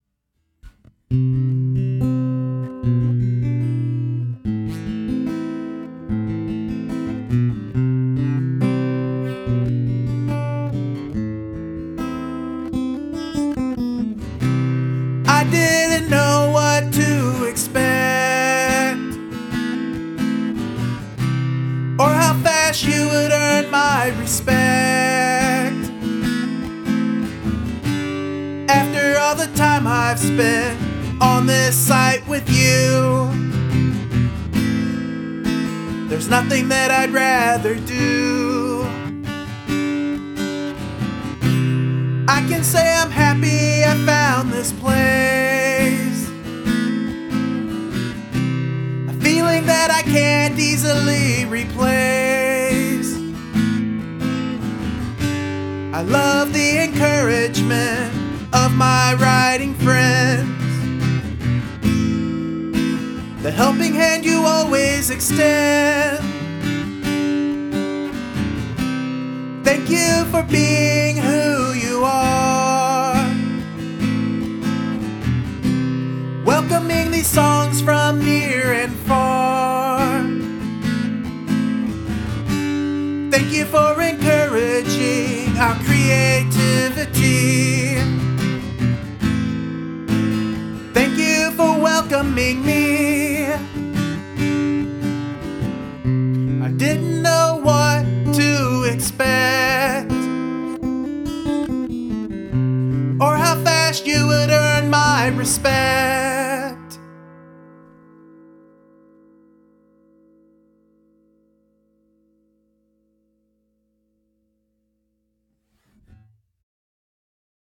The vocal melody is so catchy.